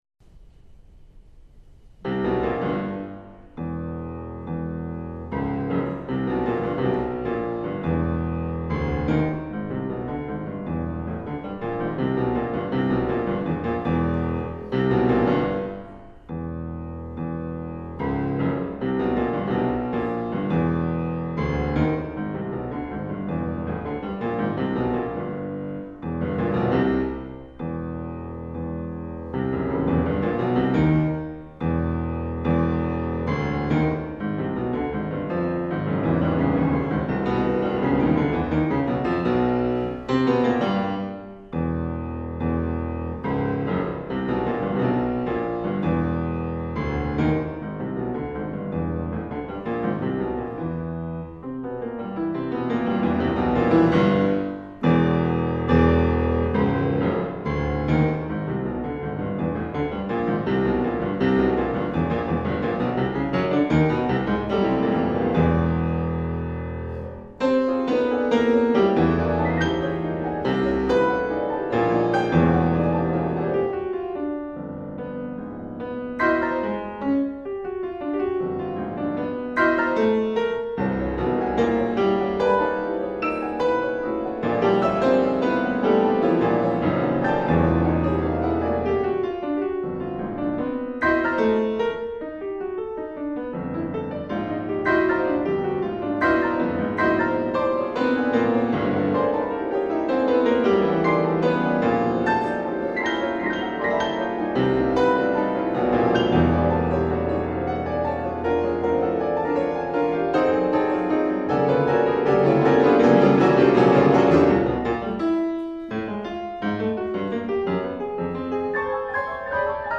Two Pianos